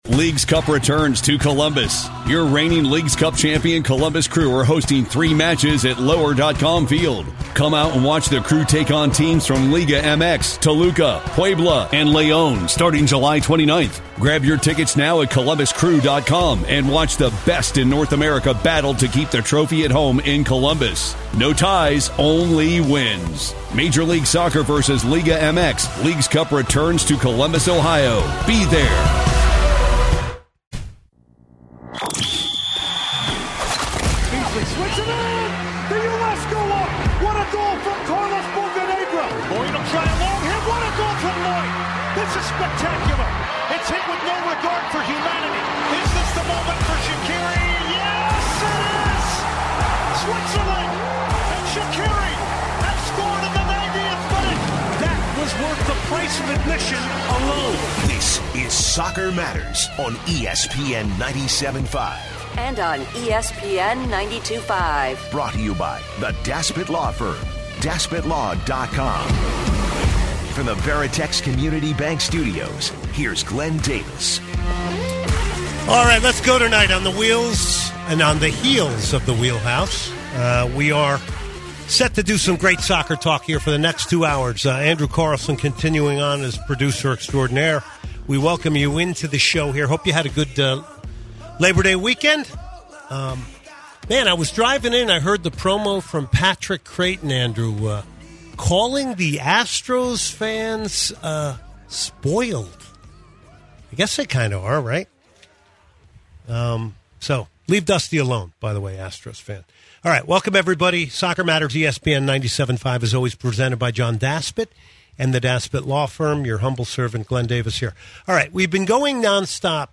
Moving on to fan calls with more over the conversation regarding the Houston Dynamos coaching situation, & more.